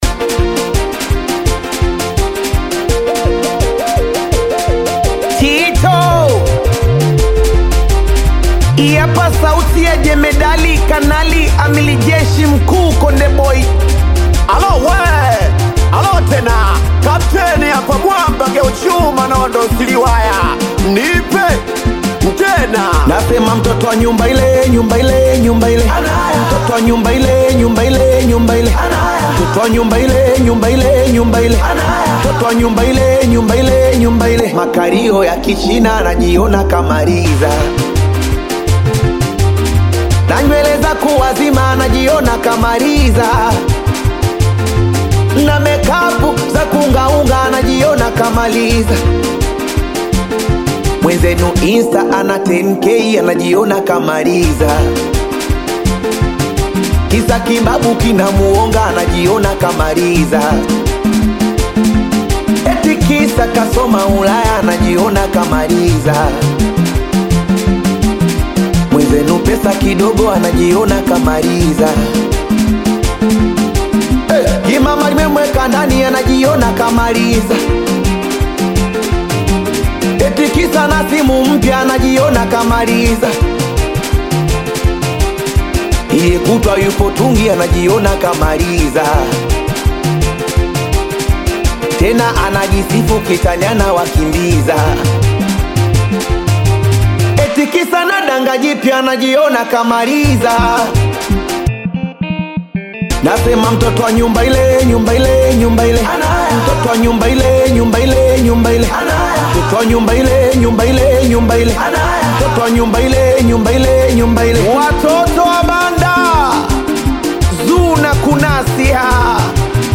Tanzanian Bongo Flava
Singeli
African Music